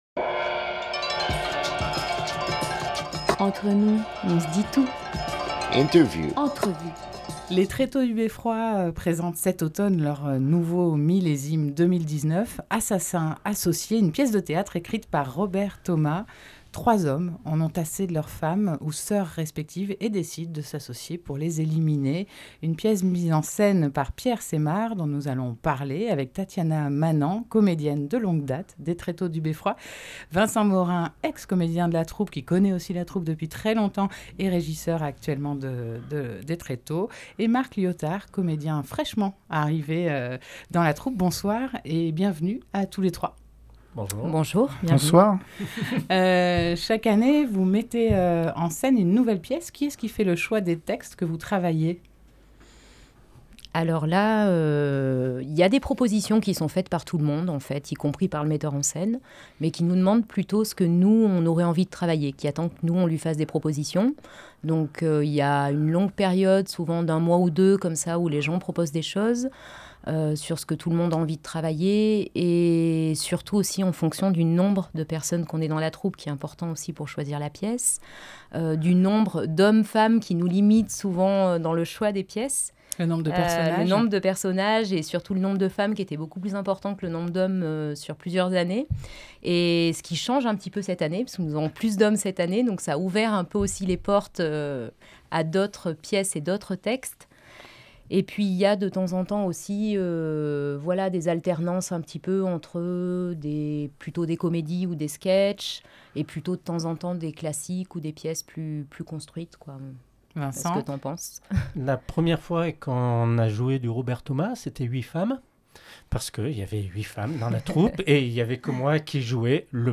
21 octobre 2019 18:25 | Interview